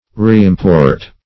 Search Result for " reimport" : The Collaborative International Dictionary of English v.0.48: Reimport \Re`im*port"\ (-p?rt"), v. t. [Pref. re- + import: cf. F. remporter.] To import again; to import what has been exported; to bring back.